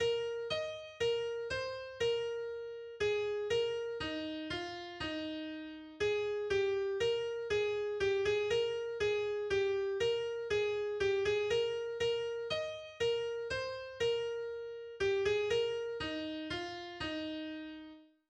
altes geistliches Volkslied und Weihnachtslied